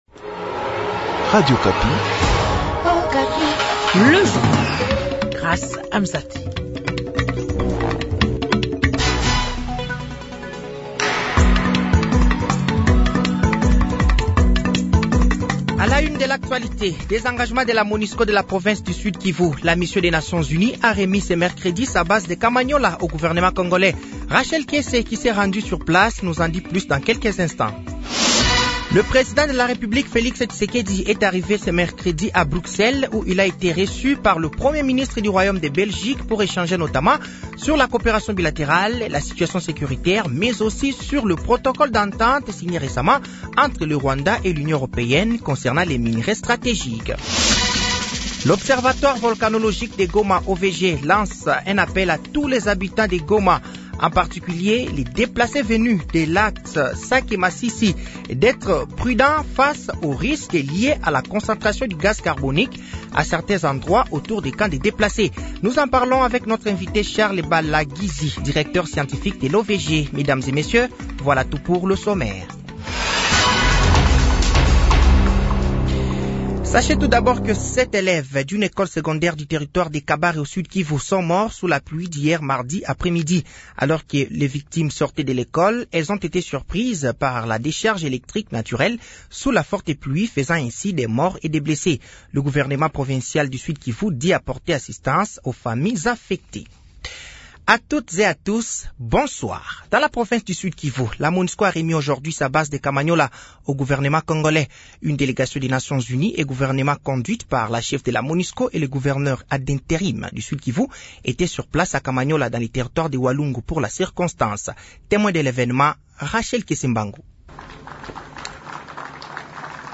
Journal Soir
Journal français de 18h de ce mercredi 28 février 2024